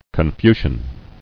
[Con·fu·cian]